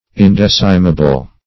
Search Result for " indecimable" : The Collaborative International Dictionary of English v.0.48: Indecimable \In*dec"i*ma*ble\, a. [Pref. in- not + LL. decimare to tithe: cf. F. ind['e]cimable.
indecimable.mp3